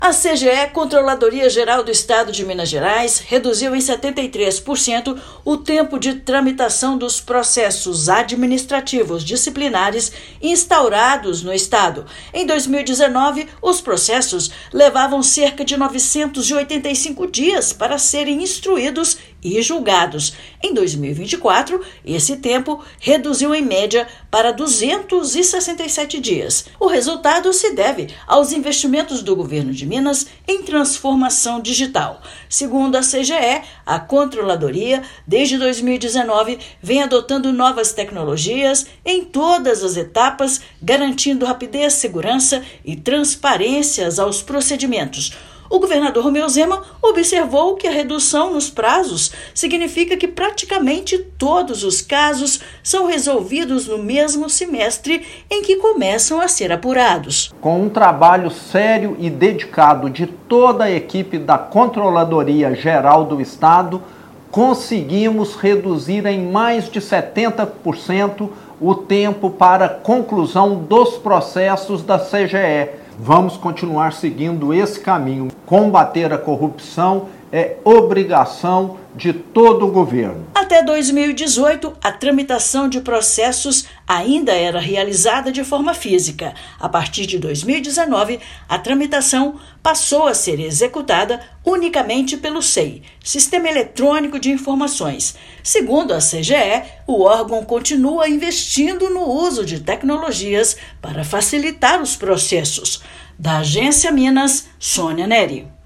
Investimento em novas tecnologias traz mais celeridade e eficiência aos processos correicionais do Estado. Ouça matéria de rádio.